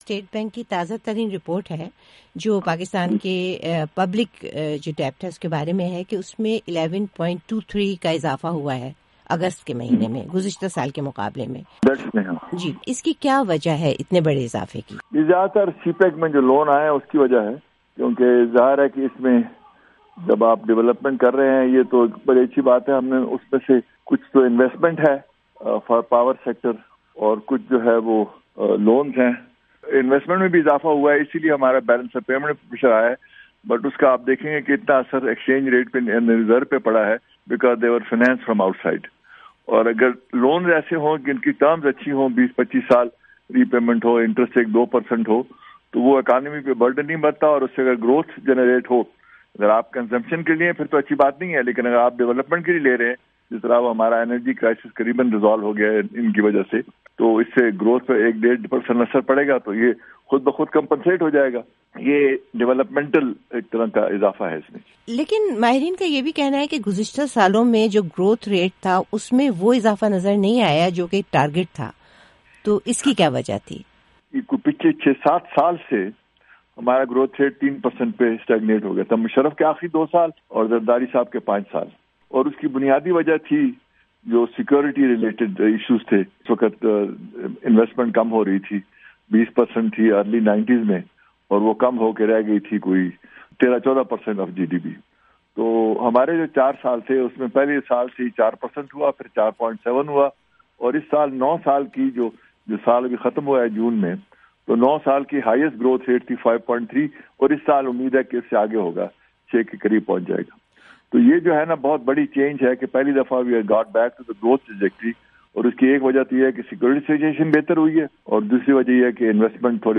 Sartaj Aziz talks with Urdu VOA